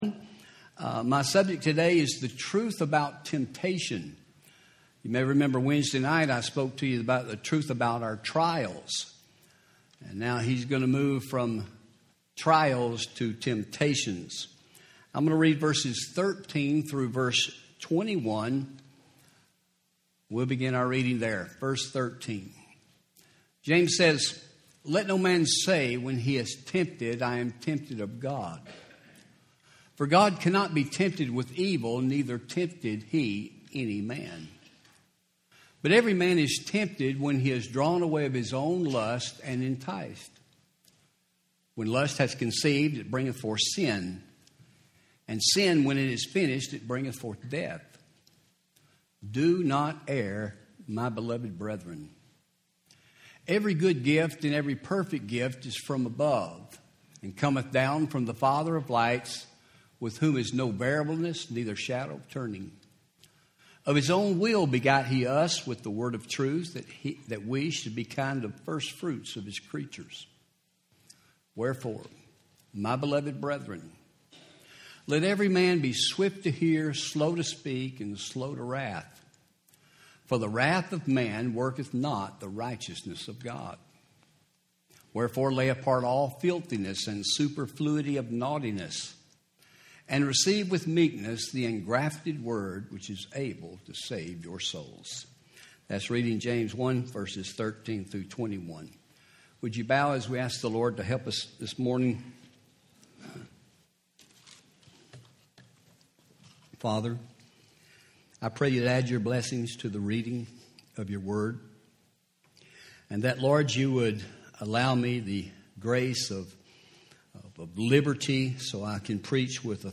Home › Sermons › The Truth About Temptation